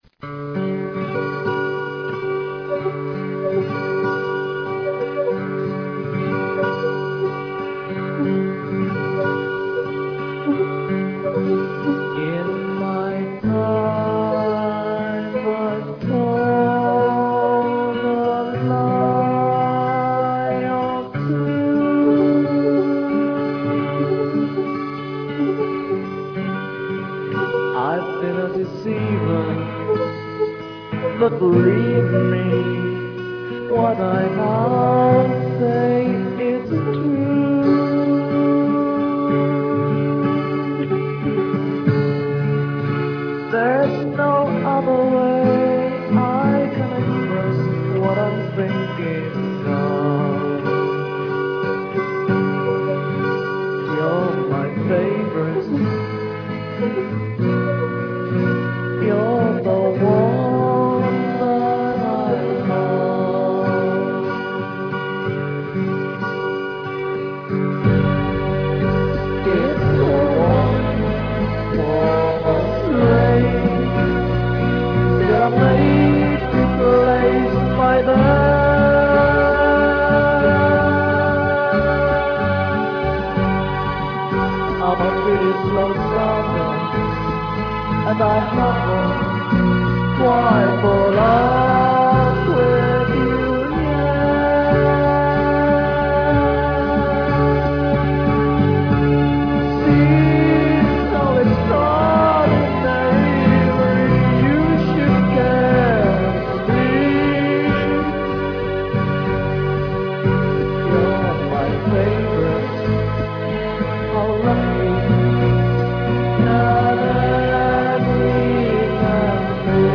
Home Recording